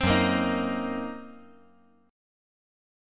F#6b5 Chord
Listen to F#6b5 strummed